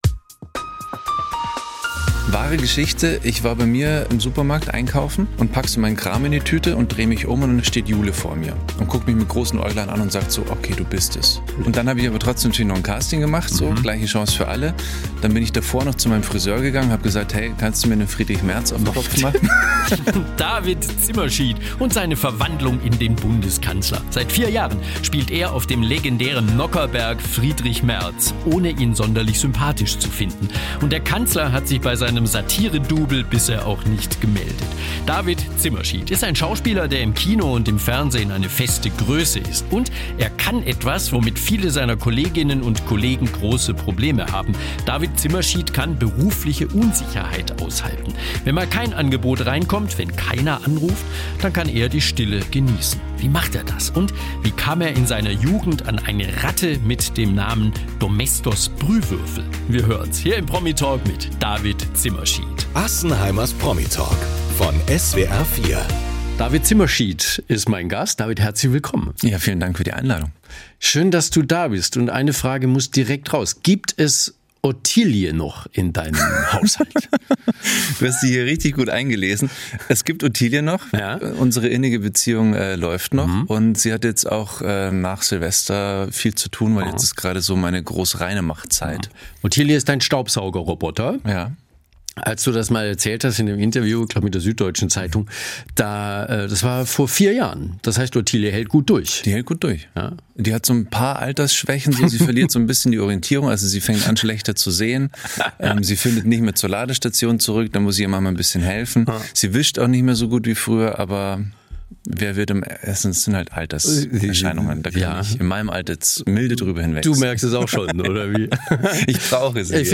im Interview
Interview